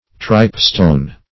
Search Result for " tripestone" : The Collaborative International Dictionary of English v.0.48: Tripestone \Tripe"stone`\ (tr[imac]p"st[=o]n`), n. (Min.)